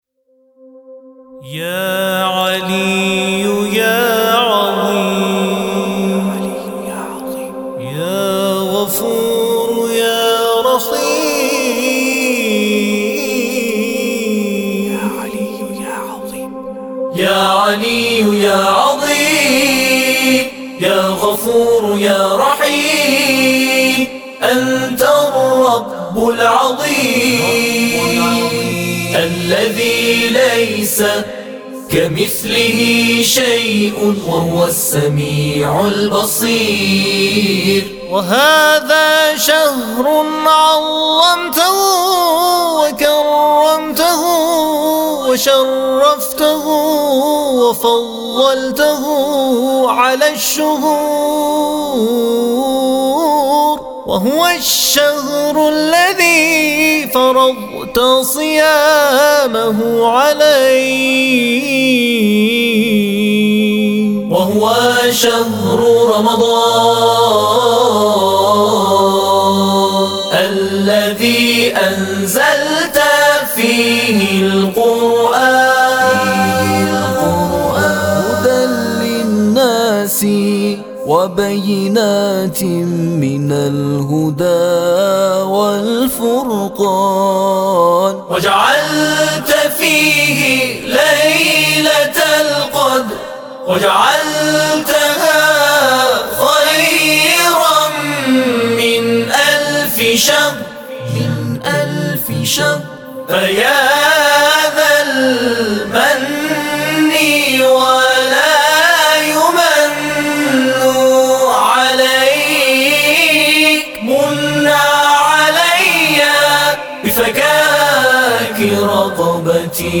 همخوانی دعای یاعلی و یاعظیم | گروه تواشیح بین المللی تسنیم